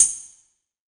WTAMBOURIN18.wav